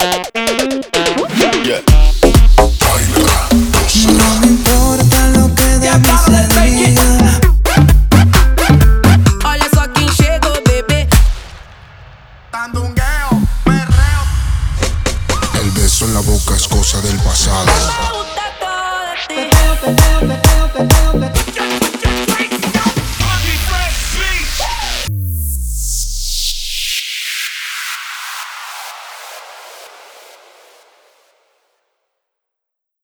temas extendidos y ritmos vibrantes